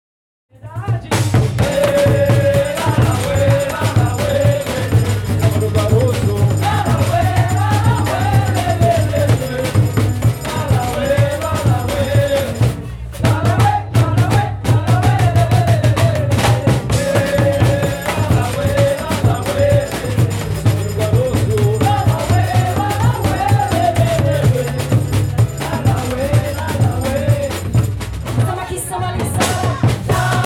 La Roda